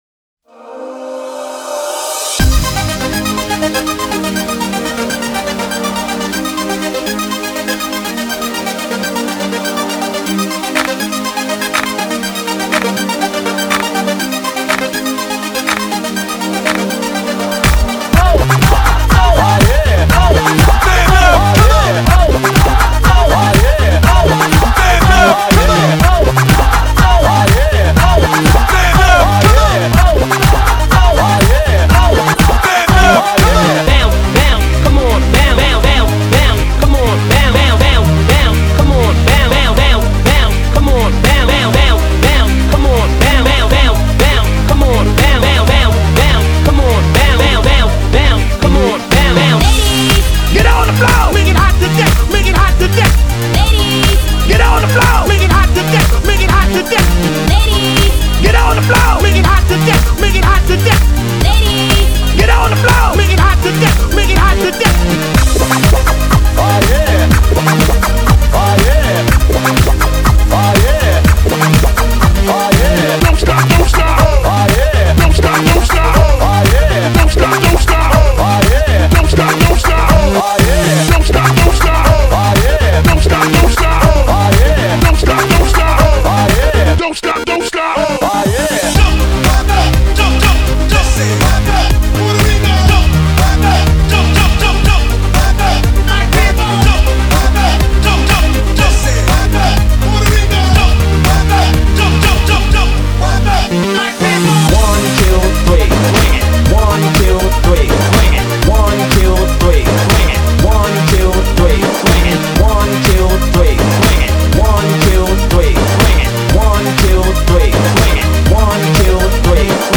Genre: 80's Version: Clean BPM: 108 Time